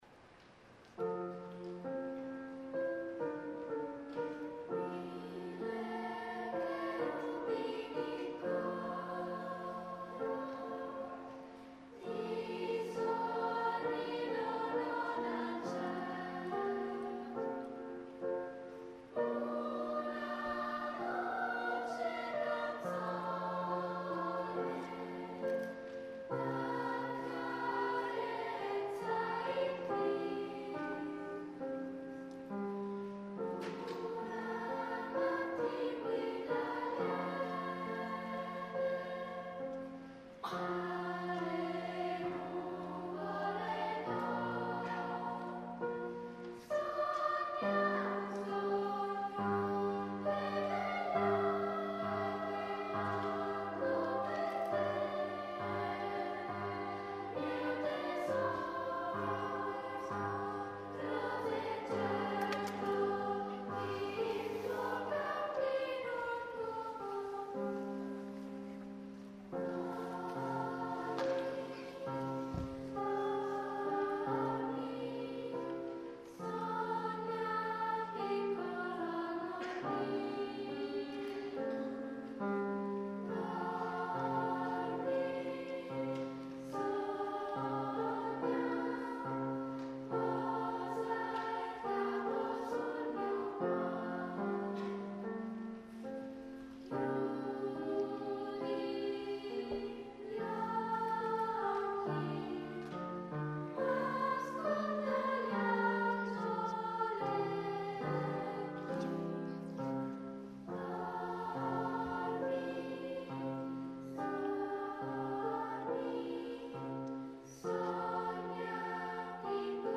IV Rassegna corale